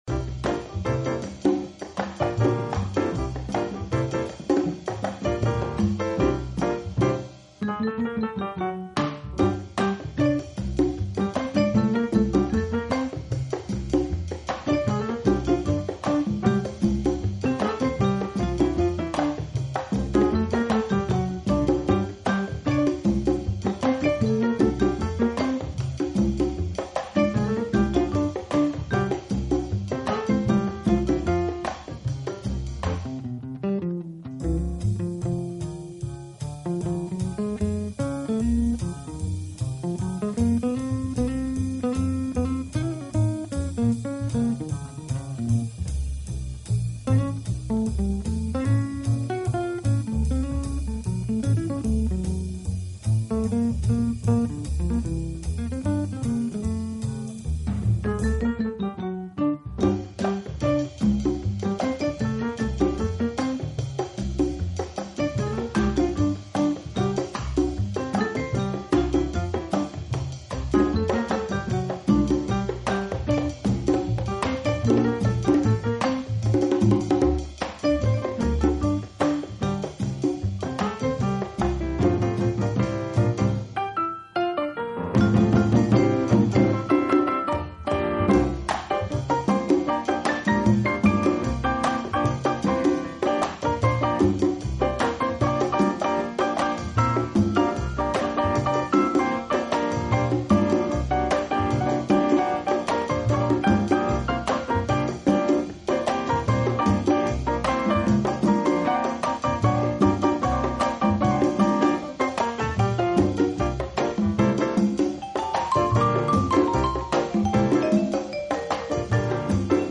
【爵士休闲】
昏暗灯光中，音乐的色彩依然鲜明，曲风摇摆生姿，游走於真实与虚幻